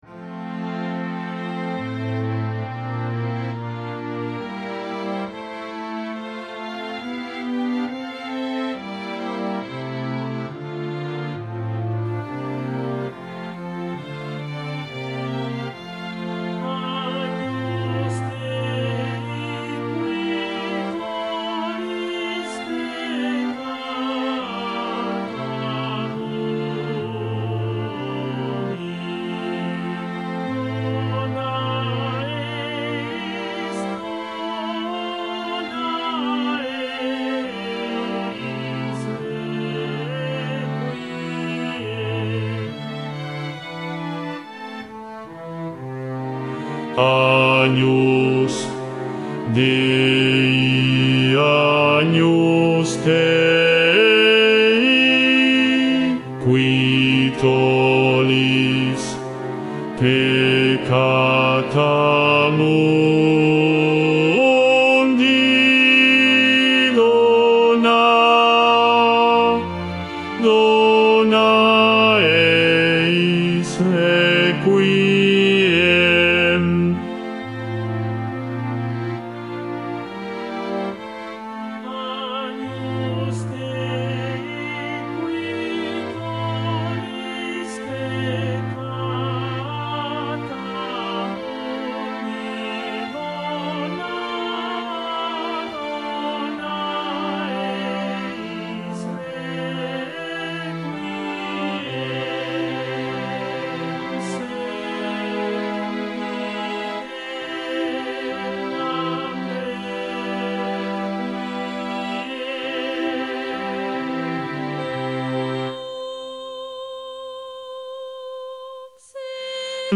Bajo I
Mp3 Profesor
5.-Agnus-Dei-BAJO-I-VOZ.mp3